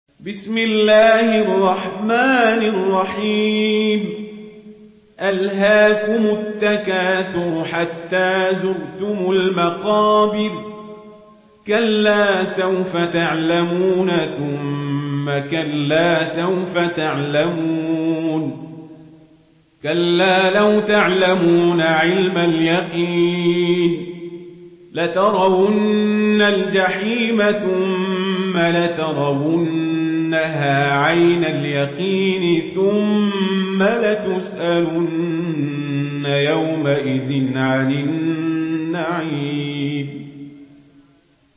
مرتل ورش عن نافع